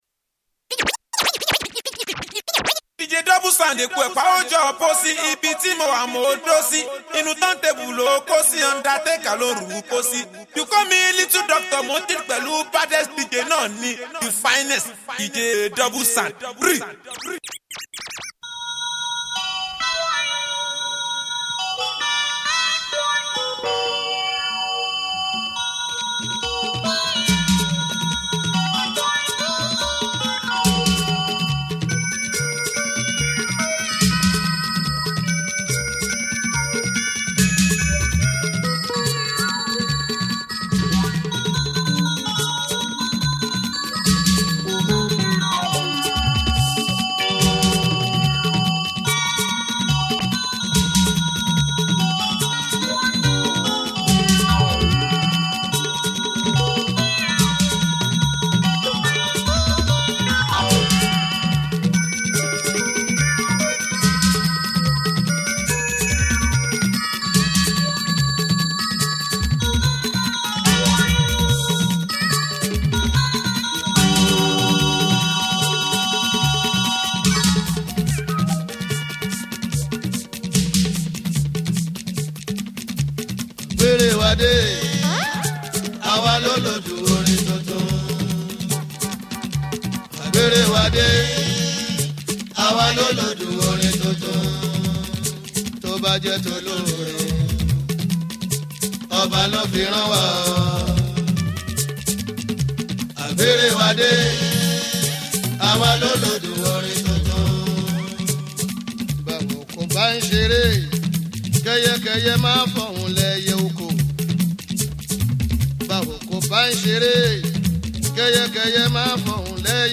Classic Nigerian deejay
Banging Mixtape
Download and enjoy fresh fuji playlist below!